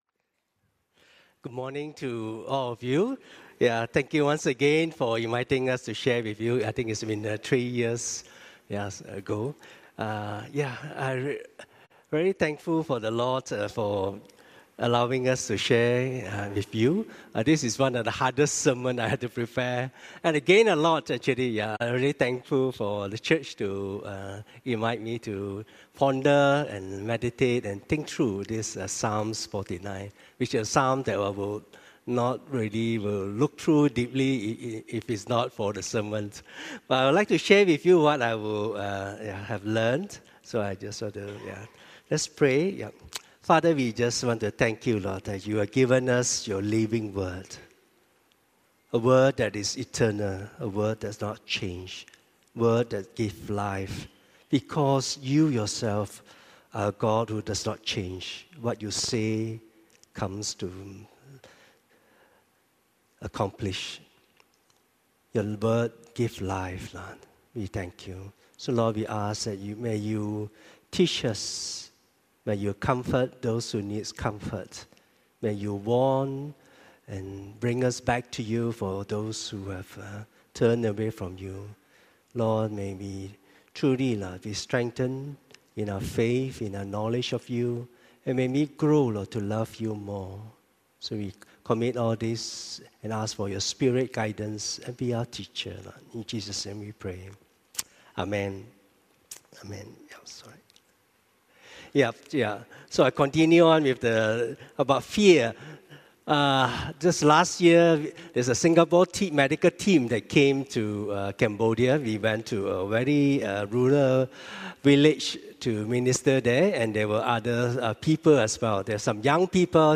Psalm 49 Preacher